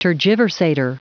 Prononciation du mot tergiversator en anglais (fichier audio)
Prononciation du mot : tergiversator